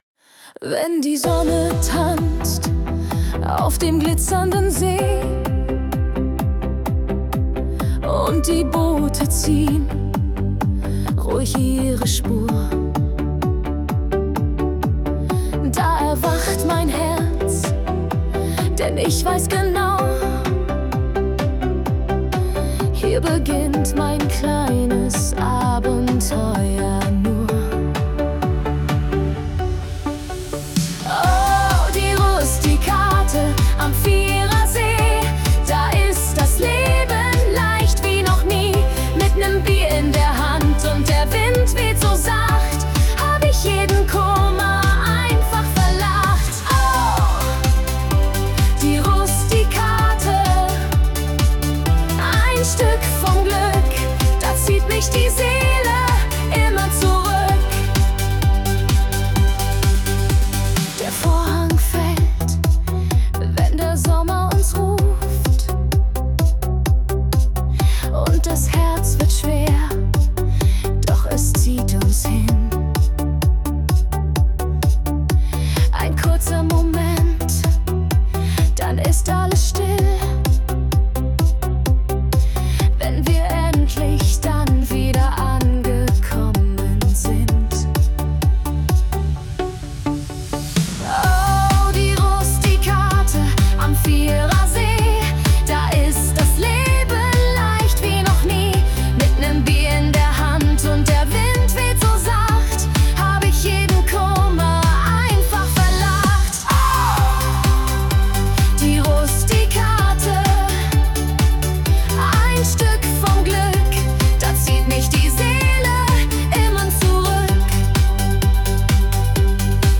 Mit eigenem “Flügel”, Beschallungsanlage und Licht.